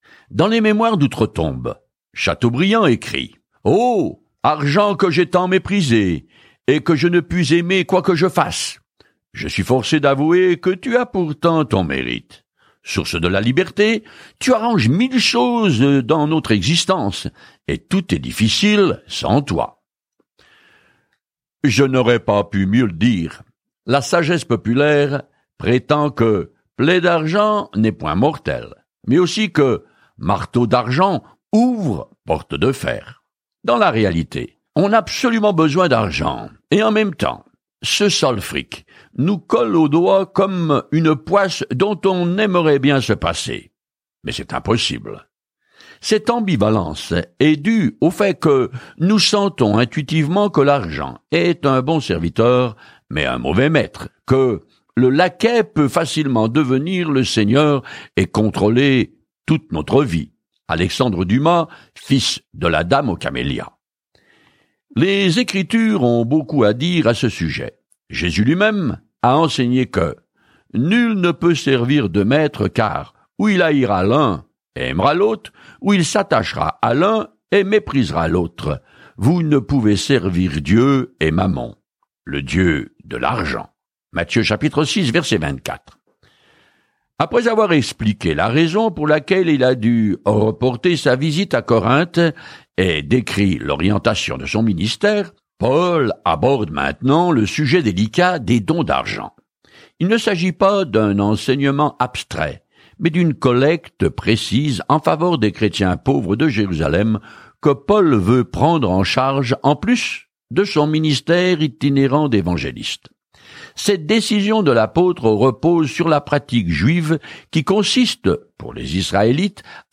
Écritures 2 Corinthiens 8:1-6 Jour 13 Commencer ce plan Jour 15 À propos de ce plan Les joies des relations au sein du corps du Christ sont mises en évidence dans la deuxième lettre aux Corinthiens lorsque vous écoutez l’étude audio et lisez certains versets de la parole de Dieu. Parcourez quotidiennement 2 Corinthiens en écoutant l’étude audio et en lisant des versets sélectionnés de la parole de Dieu.